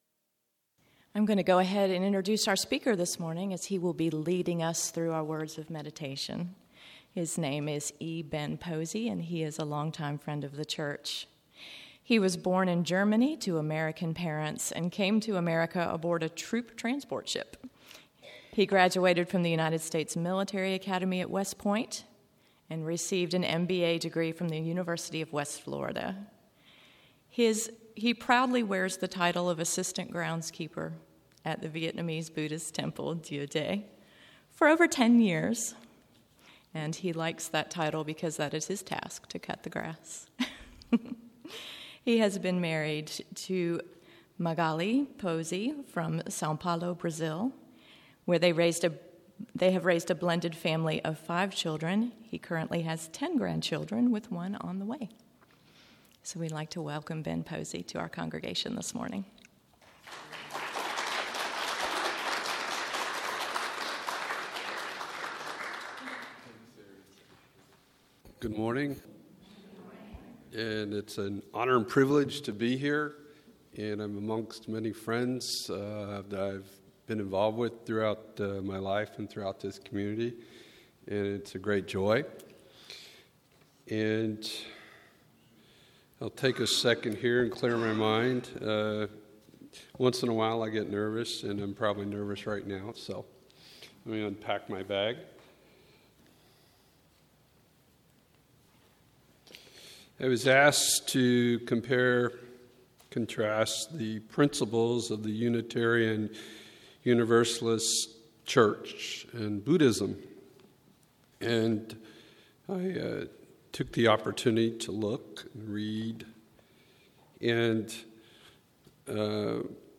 Listen to this service on our YouTube channel.